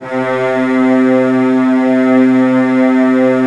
CELLOS DN3-L.wav